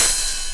Index of /90_sSampleCDs/USB Soundscan vol.20 - Fresh Disco House I [AKAI] 1CD/Partition D/01-HH OPEN